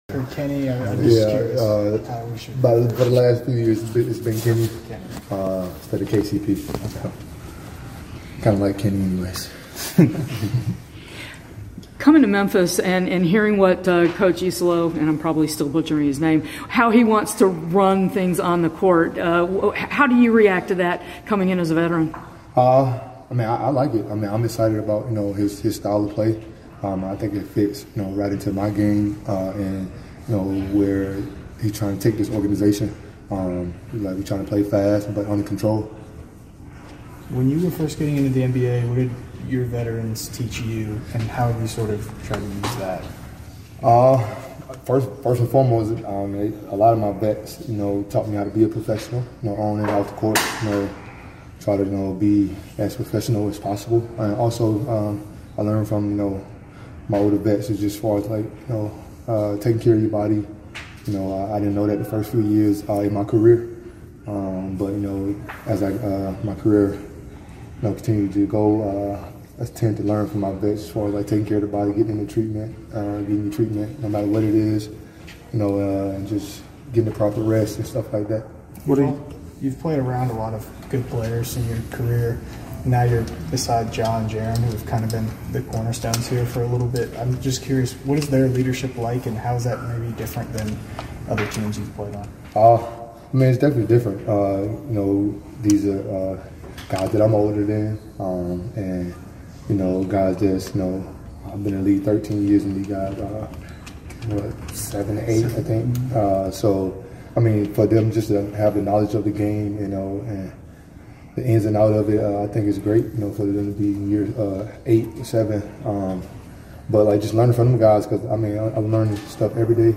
Memphis Grizzlies Guard Kentavious Caldwell-Pope Press Conference after the second day of Training Camp.